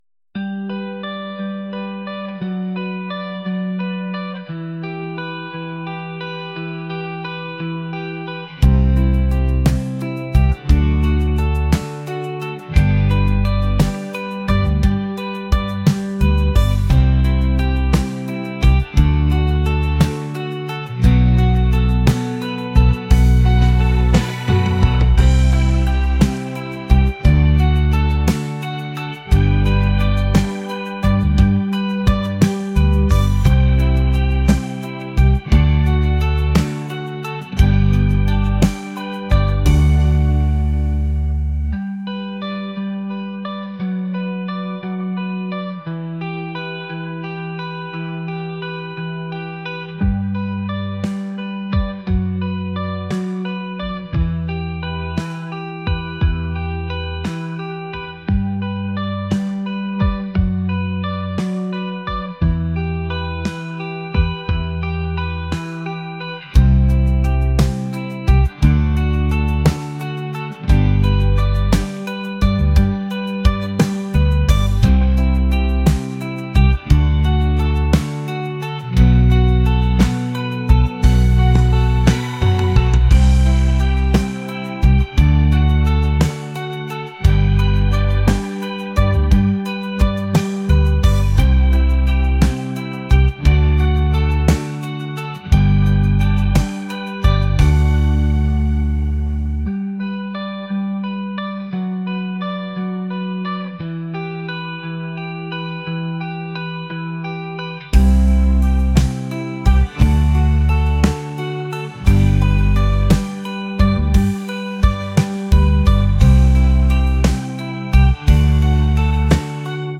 indie | pop | ambient